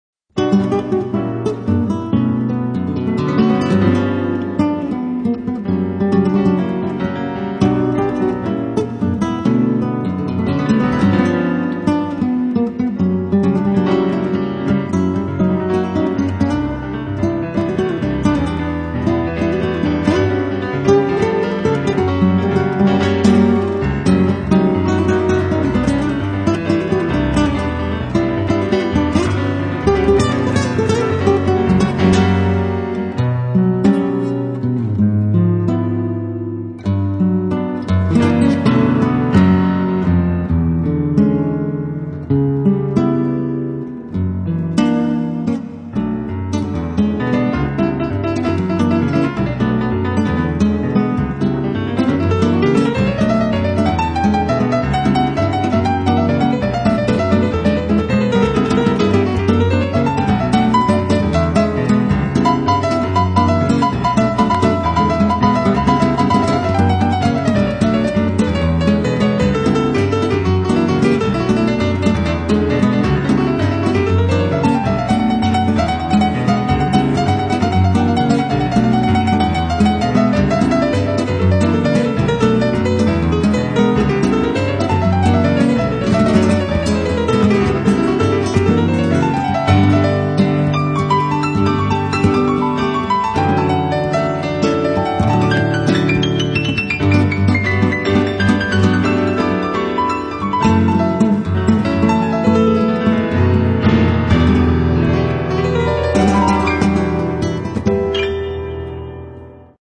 Piano
Guitare